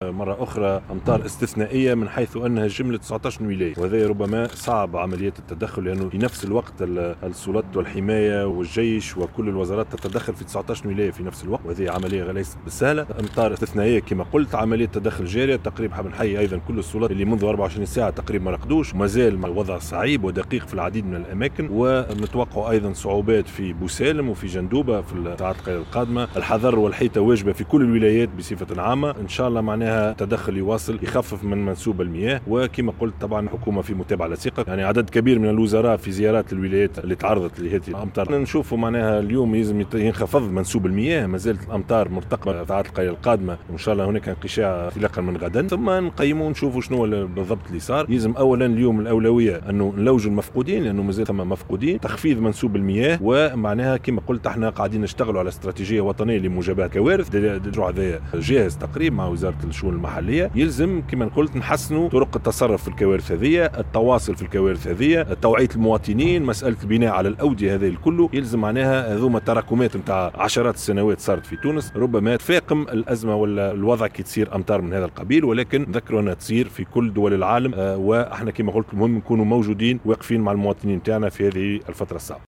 وأضاف في تصريح لمراسل الجوهرة اف ام خلال زيارته إلى القاعة المركزية للحماية المدنية بالعاصمة، أنه من المتوقع أن يزداد الوضع سوء في بوسالم وجندوبة نظرا للكميات الكبيرة من الأمطار المتوقعة خلال الساعات القادمة، داعيا إلى توخي اليقظة والحذر.